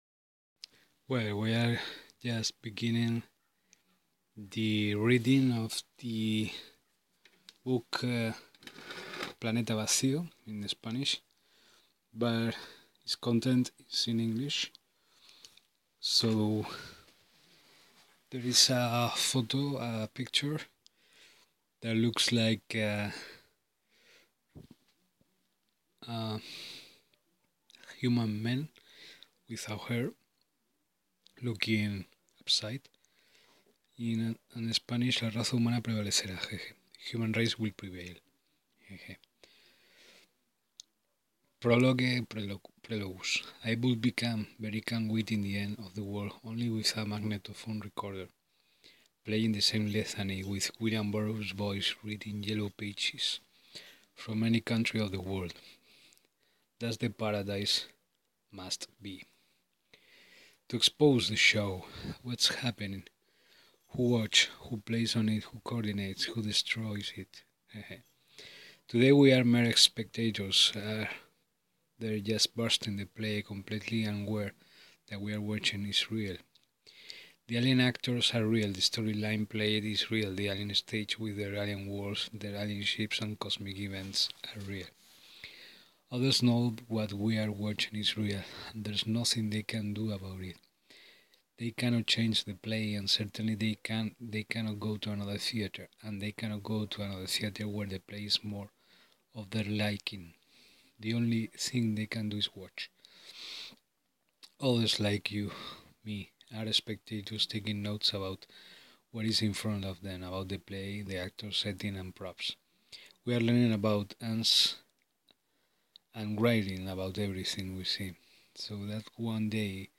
# READING PLANETA VACIO FROM BEGGINING TO PAGE 4